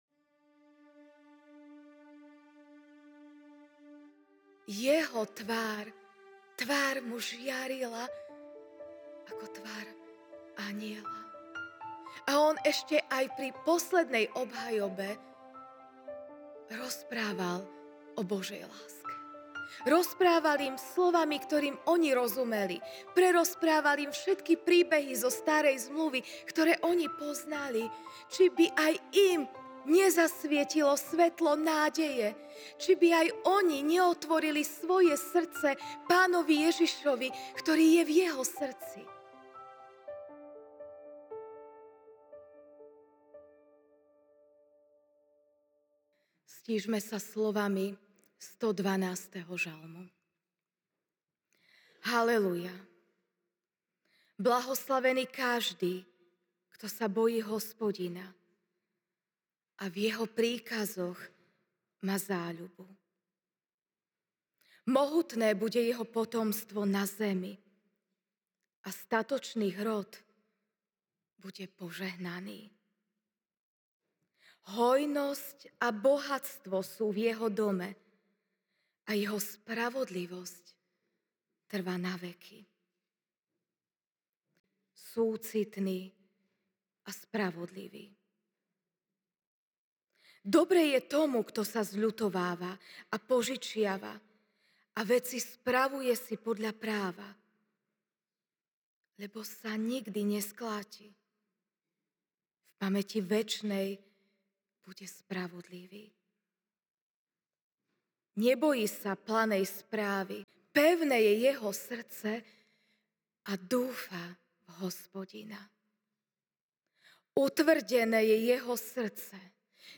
dec 26, 2024 Svetlo v nás MP3 SUBSCRIBE on iTunes(Podcast) Notes Sermons in this Series Ranná kázeň: Ž(97, 11) „ Zažiari svetlo spravodlivému a radosť tým, čo sú úprimného srdca."